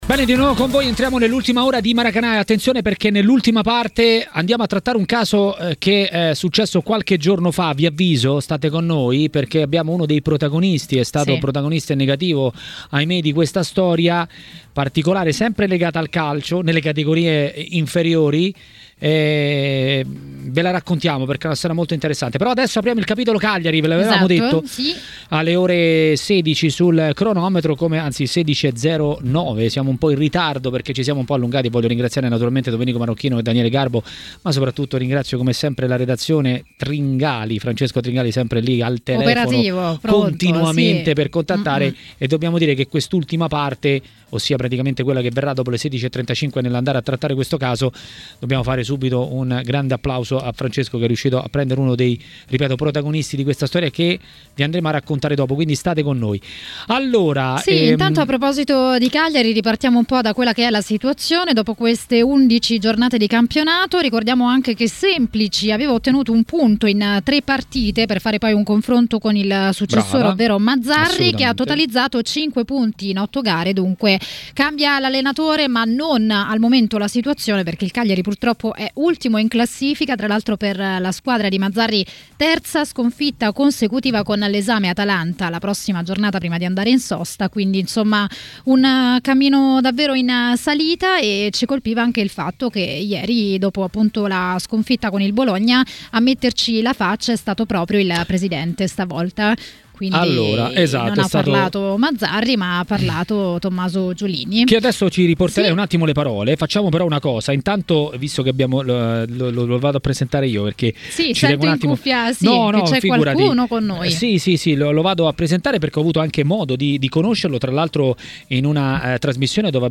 L'ex calciatore Robert Acquafresca a TMW Radio, durante Maracanà, ha detto la sua sul momento del Cagliari.